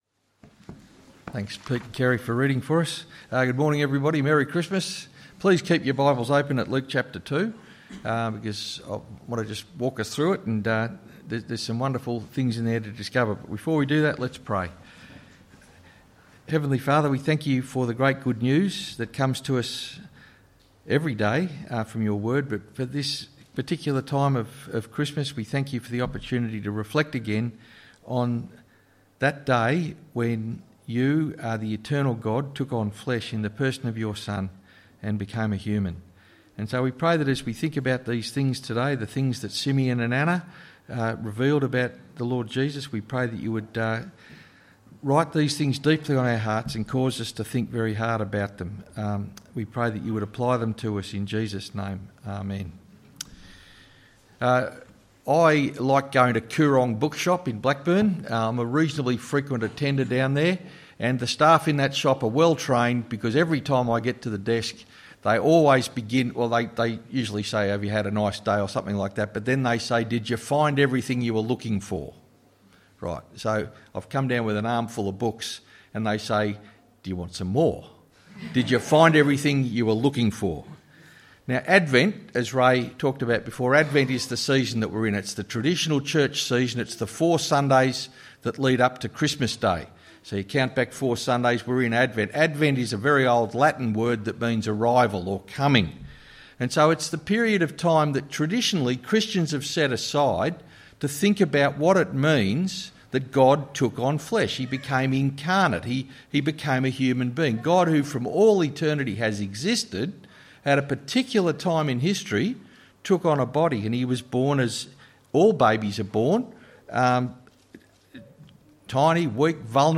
Sermon: Luke 2:22-40